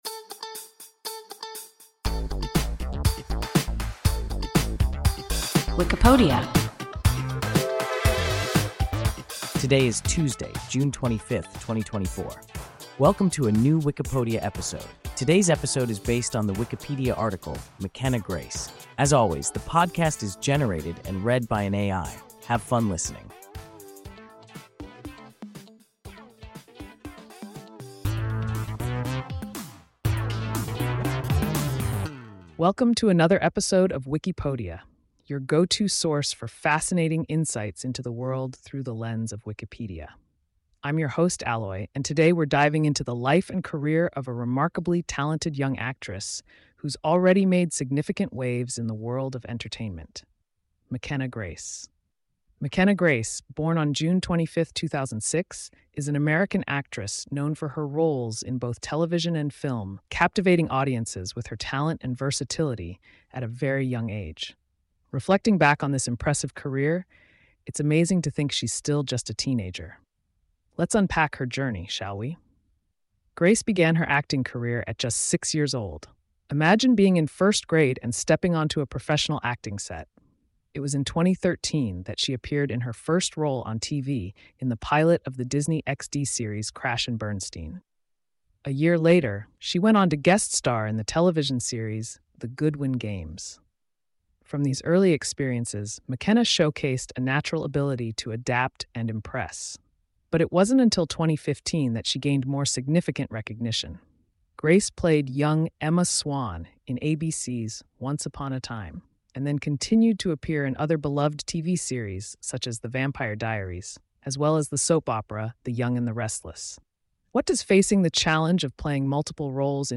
Mckenna Grace – WIKIPODIA – ein KI Podcast